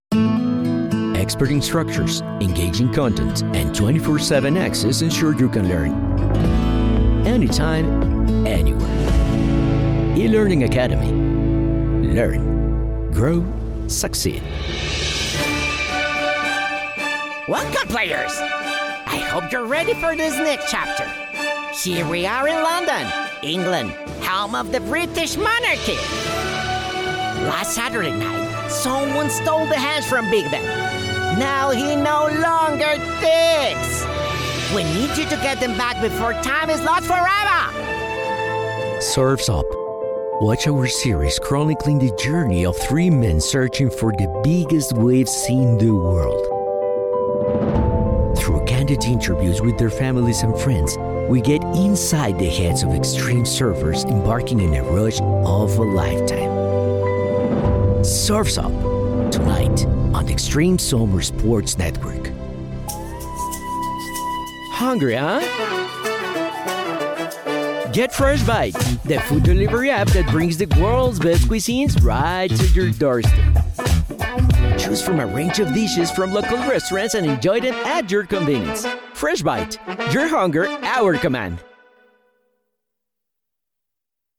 Bilingual VO Talent | Characterization and natural style my favorites.
Spanish - Neutral
Spanish - Mexican
Young Adult
Middle Aged
Commercial